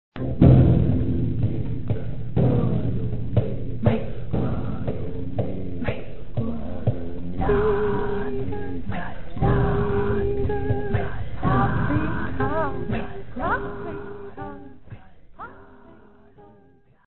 Spiel mit der Stimme
Es werden 6 Mitspieler und Ihre Stimmen gebraucht.
Jeder Mitspieler spricht eine Gemüsesorte. Dabei kann man versuchen, dem Gemüse mit der Stimme eine bestimmte „Farbe" zu verleihen (z.B. spricht man die „Gurke" ganz tief, hebt beim „Sa-lat" bei der 2. Silbe die Stimme, spricht „Mais" sehr rhythmisch, usw.).
Am Anfang ist es einfacher, wenn ein Mitspieler das Metrum (•) durchgängig klopft.